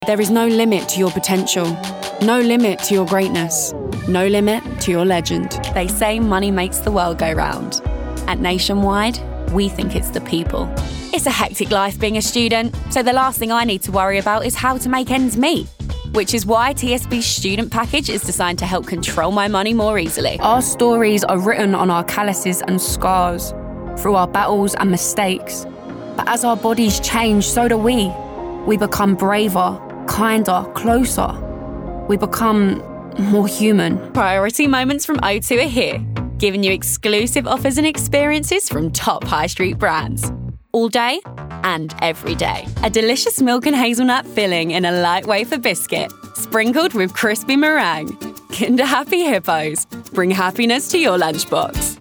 Commercial Reel
RP ('Received Pronunciation')
Light, Energetic, Young, Approachable